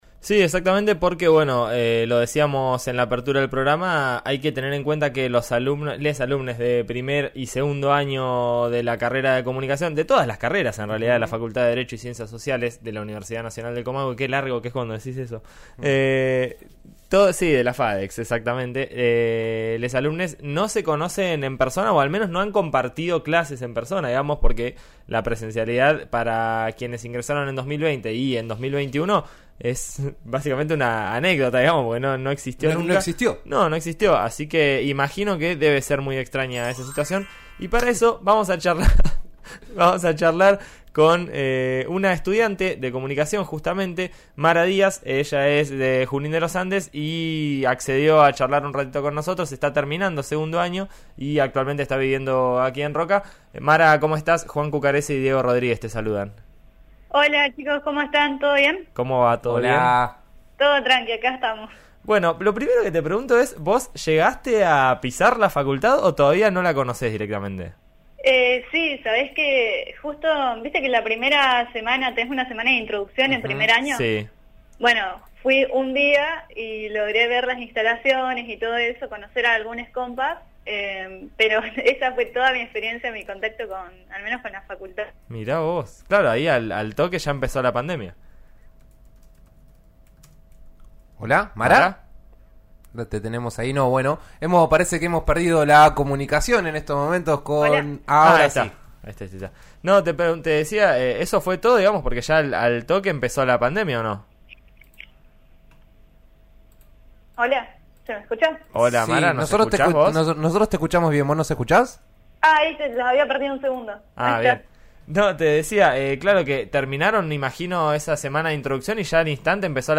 El objetivo es que los chicos 1° y 2° año de la carrera se conozcan en persona tras dos años de virtualidad absoluta. En eso estamos de RN Radio dialogó con una estudiante en la previa de la reunión.